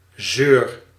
Ääntäminen
France (Paris): IPA: /si/